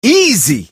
brock_kill_vo_03.mp3